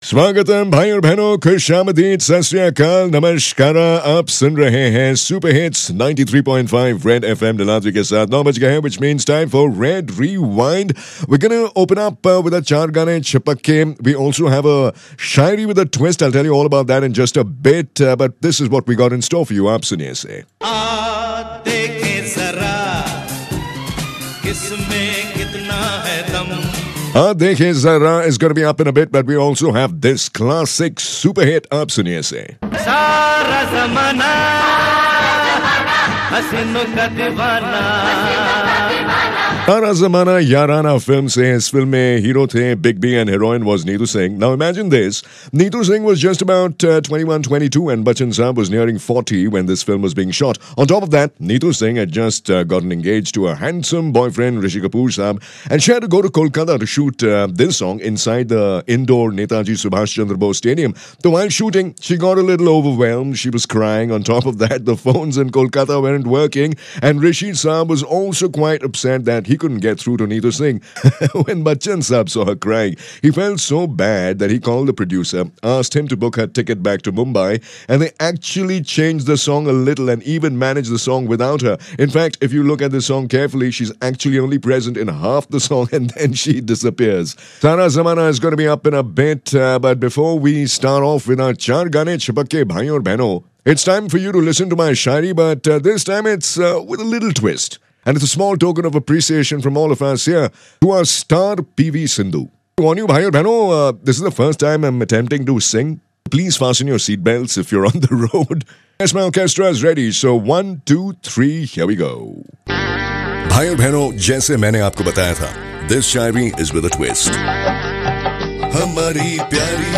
When the heaviest voice of the country starts singing... this is what happens...